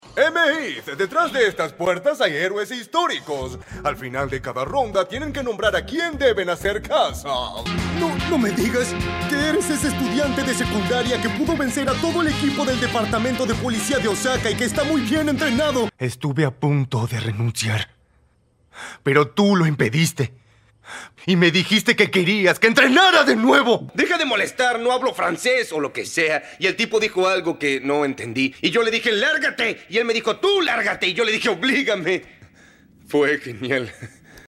Actor de doblaje · Locutor
Películas y series